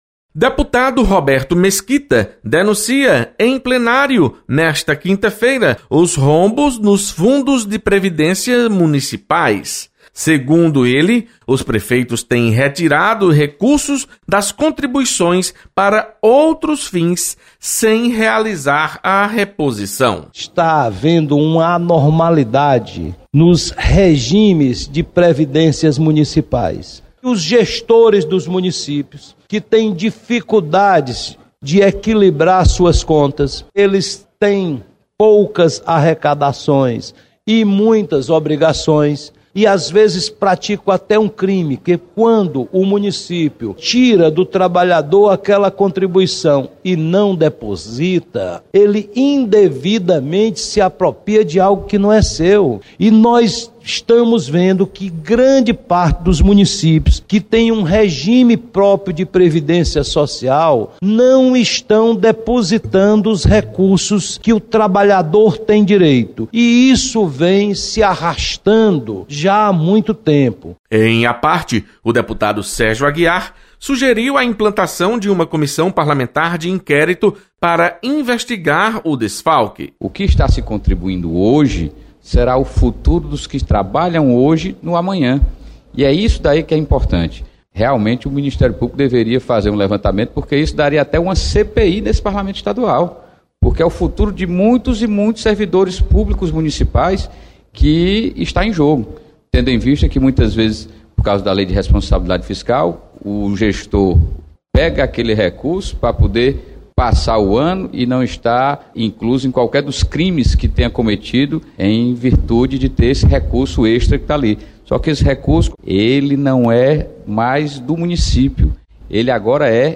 Deputados mostram preocupação com rombos nos fundos de previdência dos municípios. Repórter